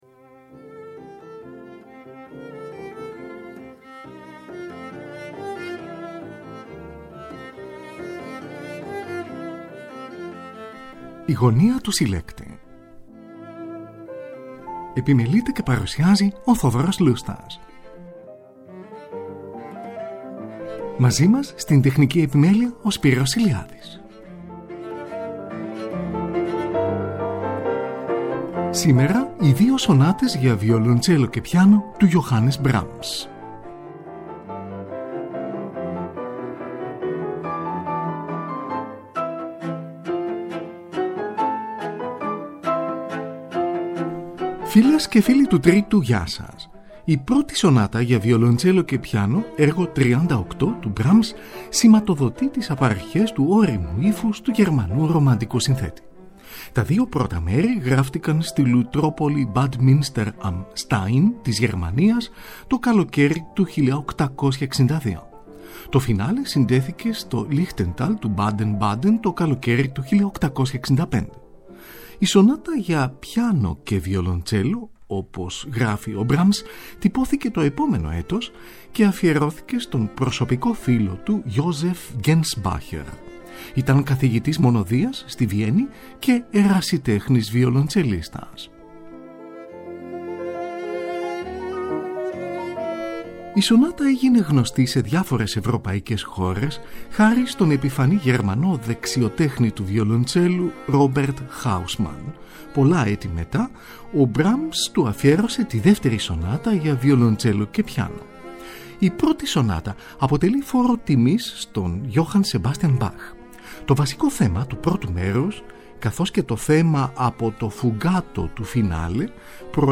Τον βιολοντσελίστα Antonio Janigro συνοδεύει ο πιανίστας Jörg Demus, από ζωντανή ηχογράφηση στη Ρώμη, στις 15 Μαΐου 1957.